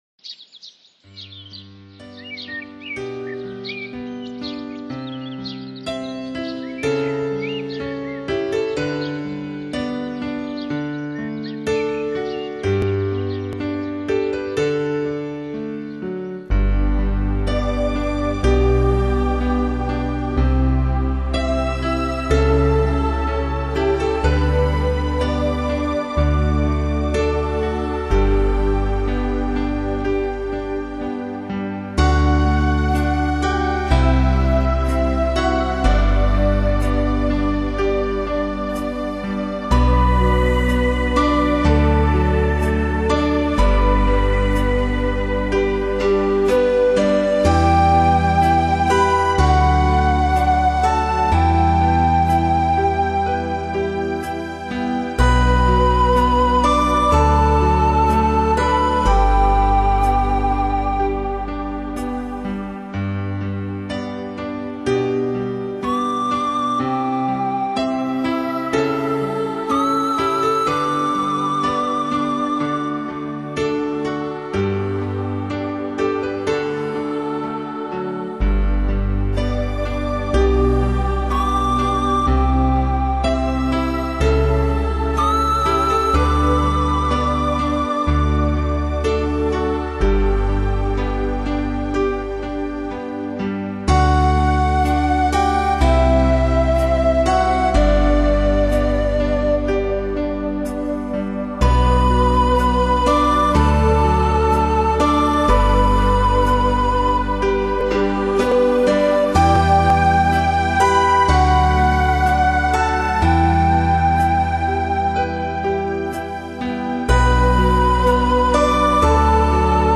以如梦似幻的清新自然音
音质而风靡全国，独具一格的空灵乐风，让聆听者随之倾倒。
微风，细雨，虫